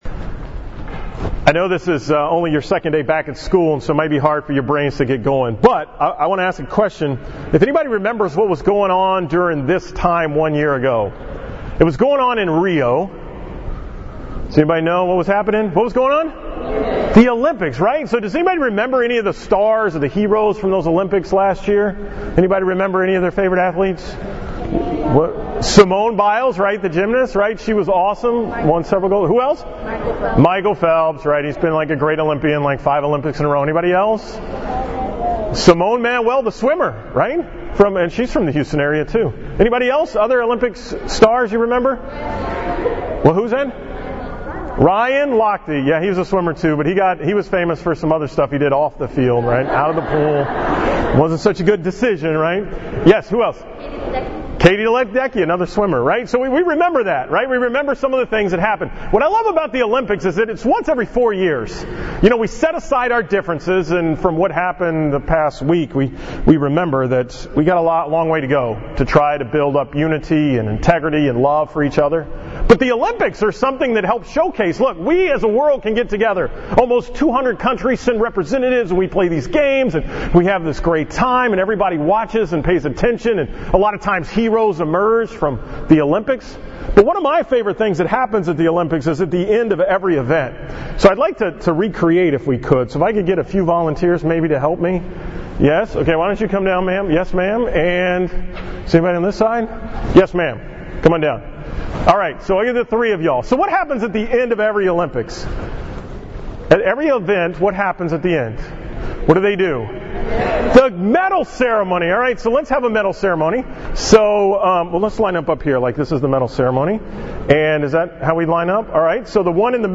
From the Mass on August 15, 2017 at IWA.